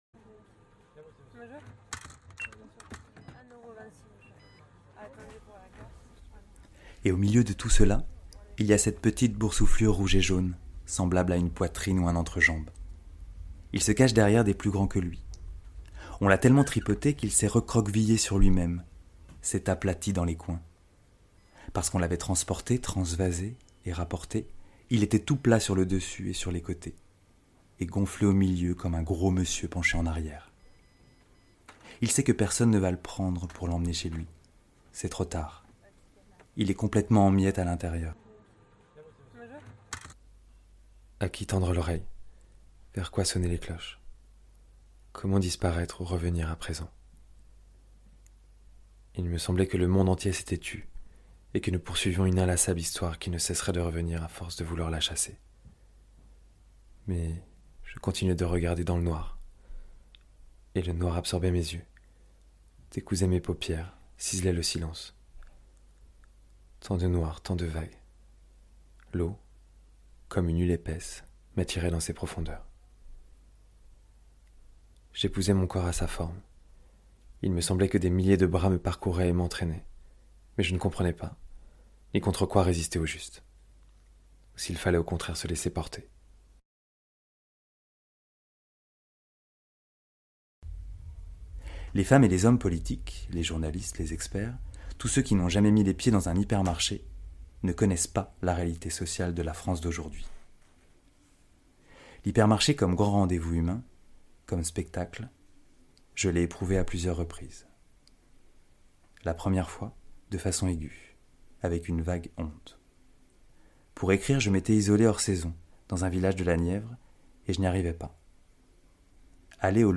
Voix off
Demo